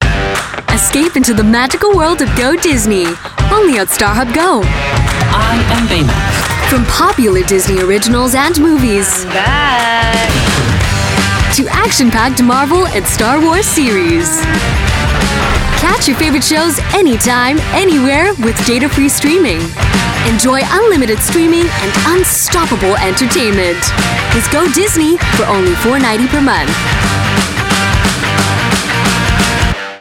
Voice Sample: Starhub Go Disney
EN Asian EN SG
We use Neumann microphones, Apogee preamps and ProTools HD digital audio workstations for a warm, clean signal path.